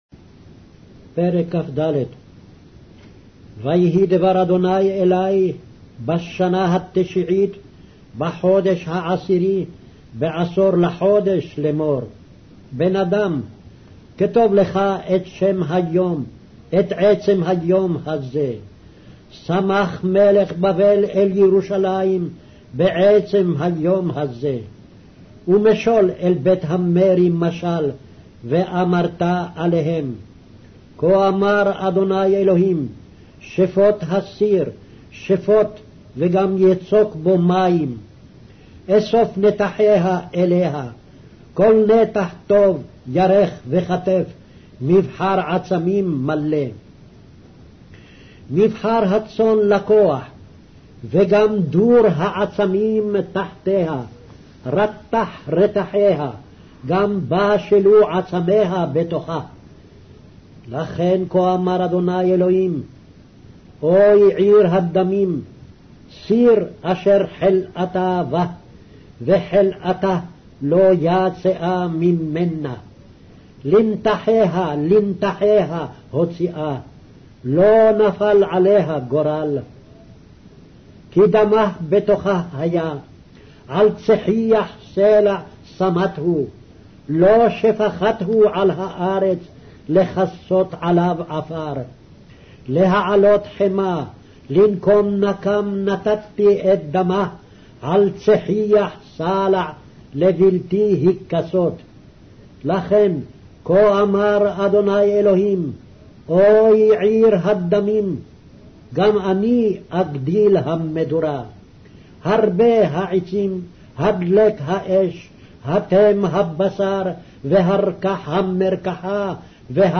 Hebrew Audio Bible - Ezekiel 40 in Irvhi bible version